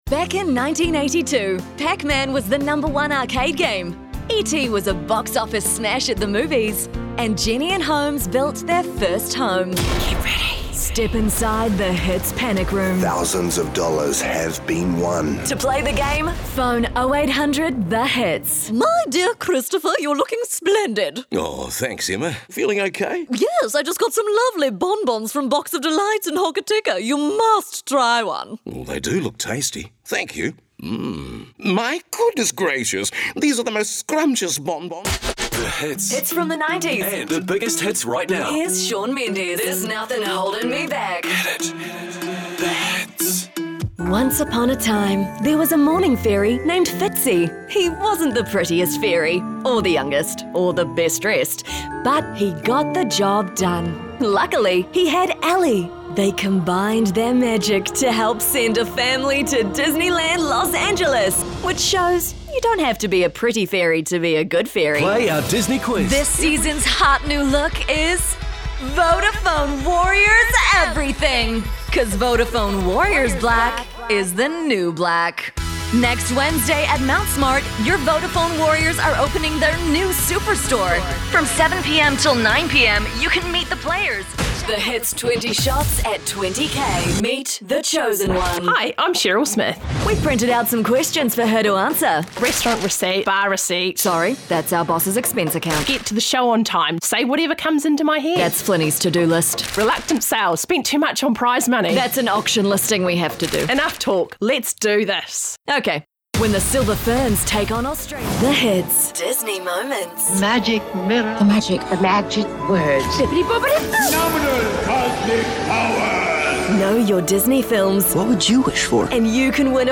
Demo
Adult
new zealand | natural
ANIMATION 🎬
COMMERCIAL 💸